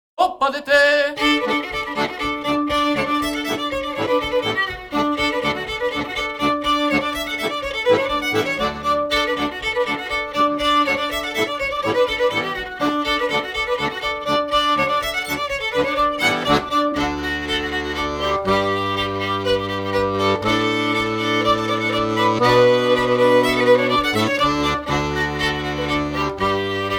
danse : pas d'été
Pièce musicale éditée